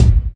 Studio Steel Kick Drum Sample C Key 370.wav
Royality free steel kick drum sound tuned to the C note. Loudest frequency: 205Hz
studio-steel-kick-drum-sample-c-key-370-2Vf.ogg